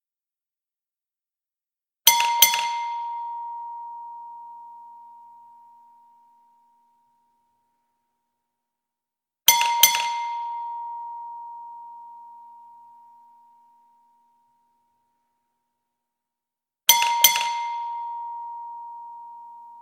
gas station bell
automotive bell car ding fuel gas-station gas-station-bell petrol sound effect free sound royalty free Sound Effects